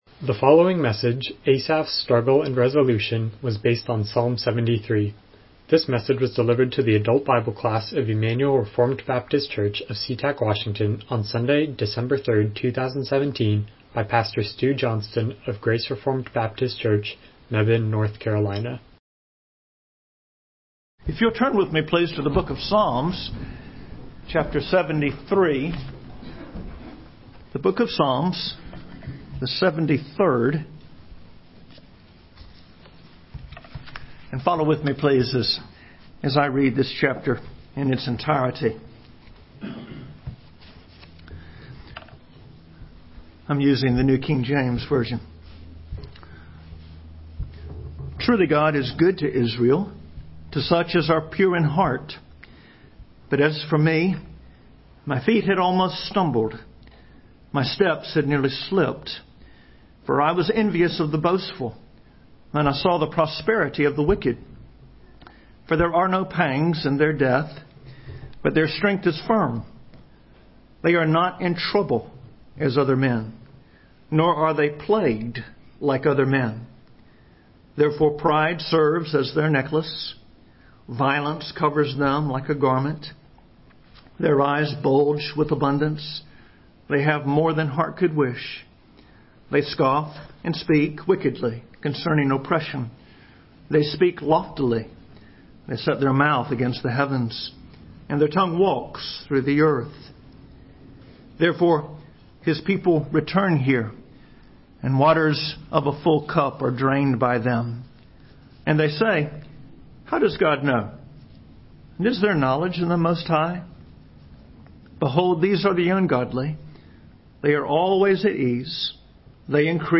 Miscellaneous Passage: Psalm 73:1-28 Service Type: Sunday School « Who Is the King?